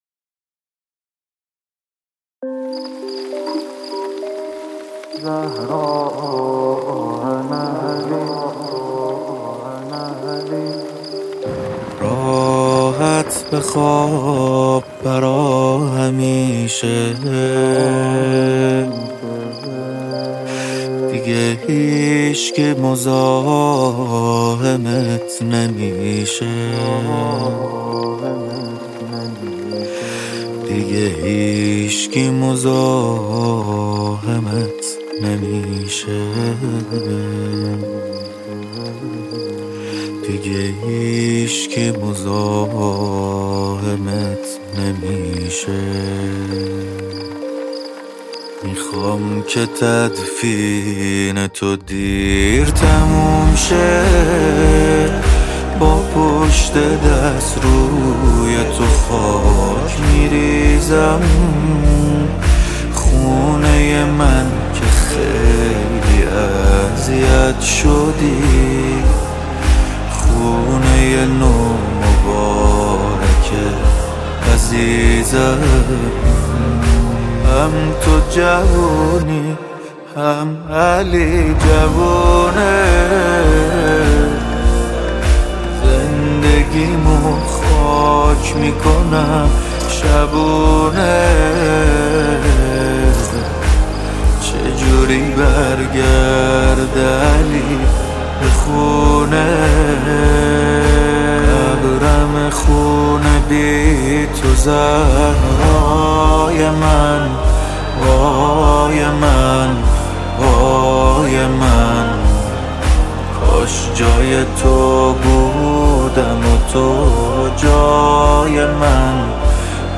ویژه ایام فاطمیه